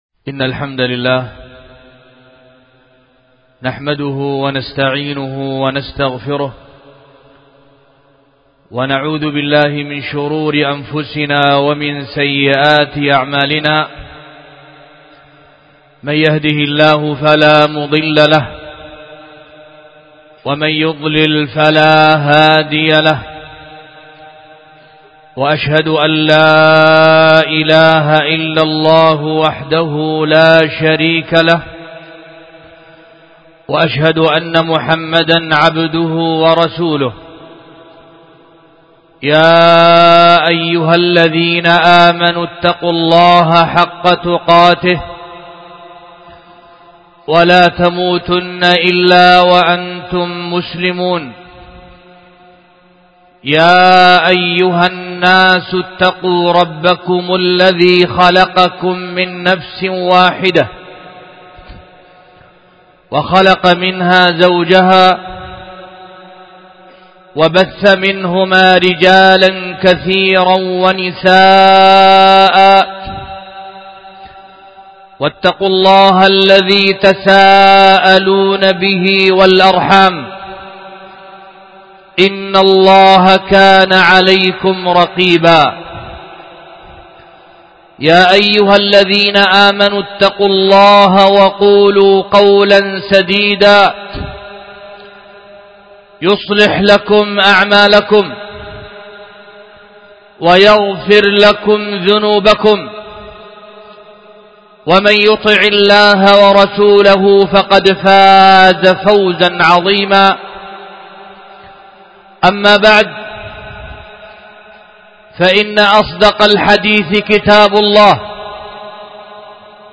خطبة الجمعة
القيت في دار الحديث في مدينة دار السلام العلمية بيختل المخا